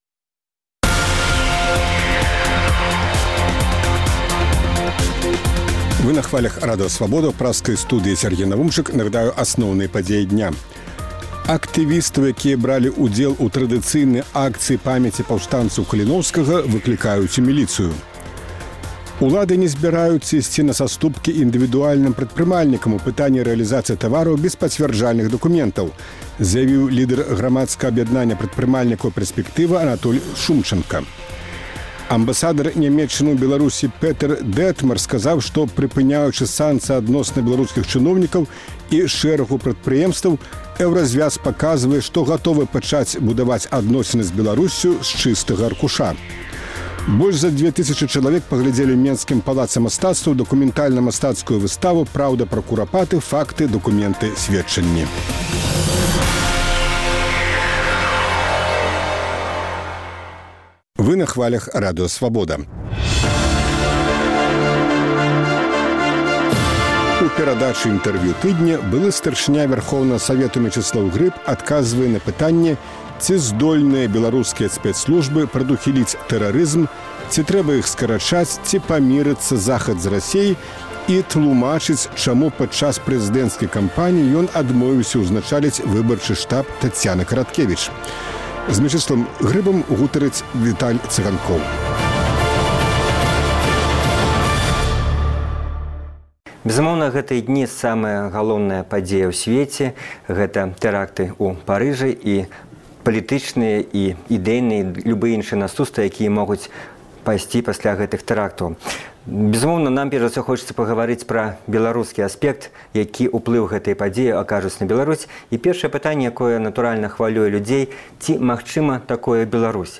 Інтэрвію тыдня: Мечыслаў Грыб